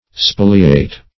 Spoliate \Spo"li*ate\ (sp[=o]"l[i^]*[=a]t), v. t. & i. [imp. &